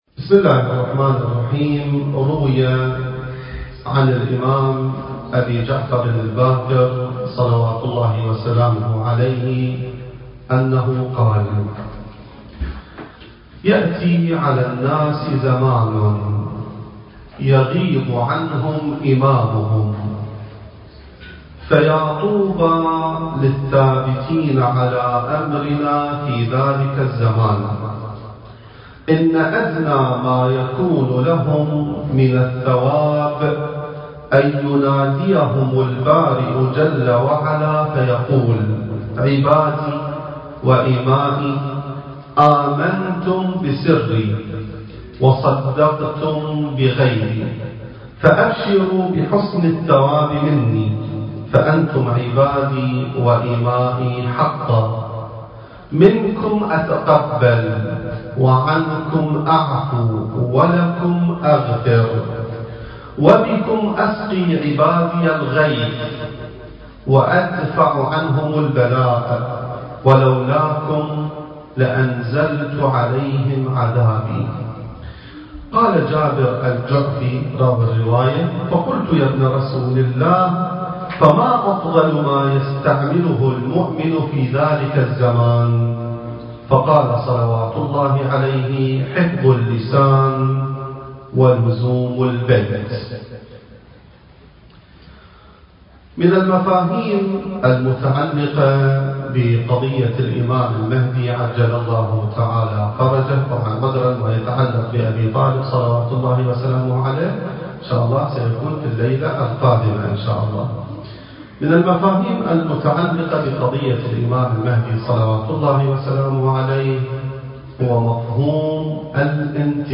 المكان: مؤسسة الإمام السجاد (عليه السلام) / استراليا التاريخ: 2019